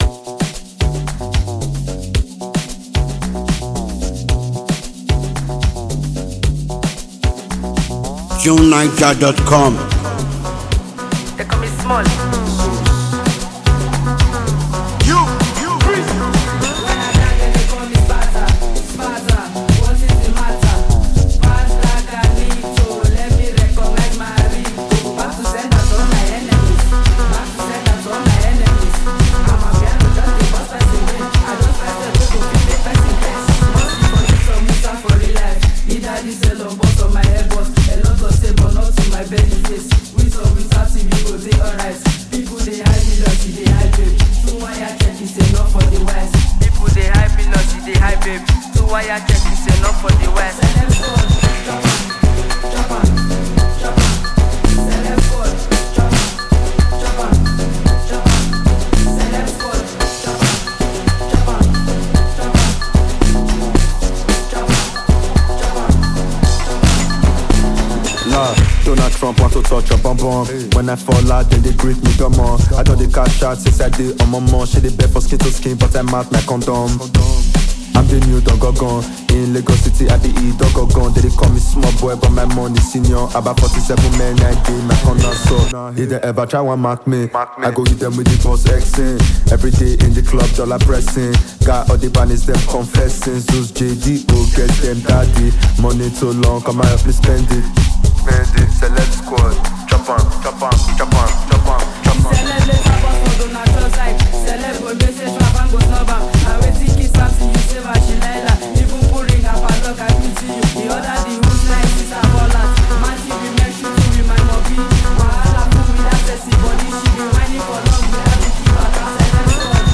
a prominent Nigerian female vocalist and song writer
captivating and lively song